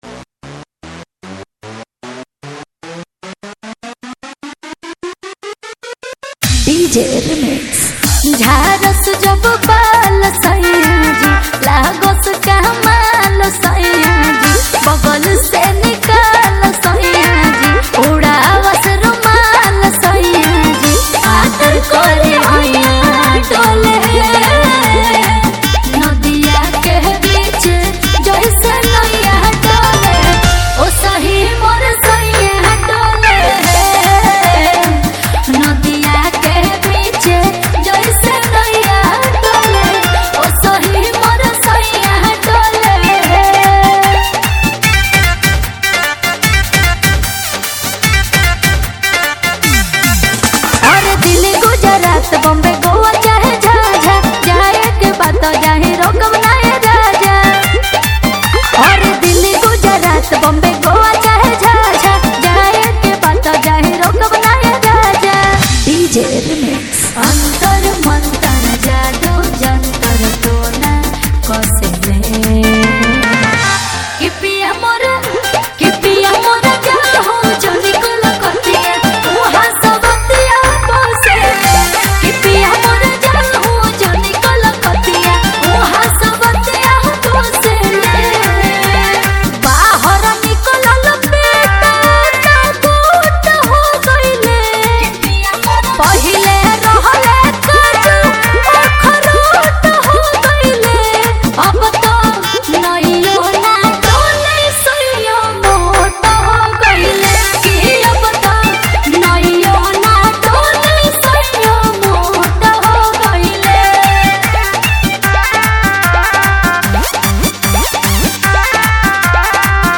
Bhojpuri Romantic DJ Remix